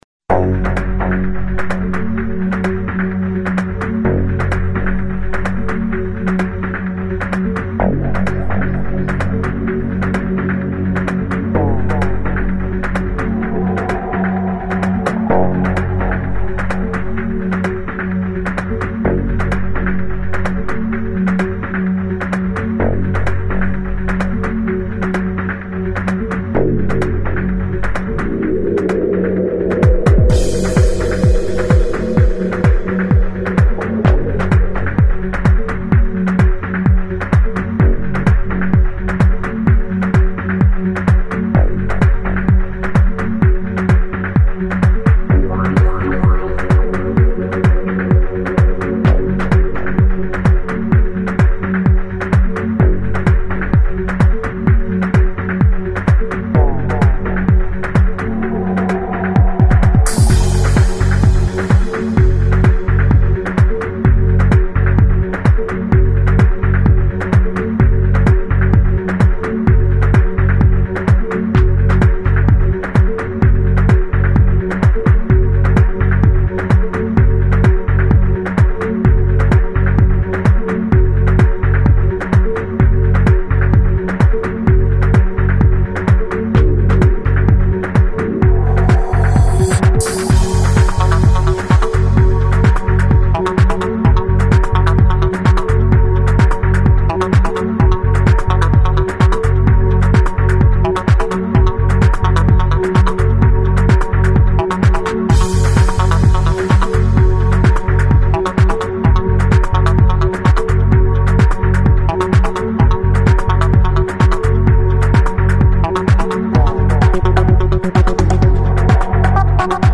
A shorter, edited version of a track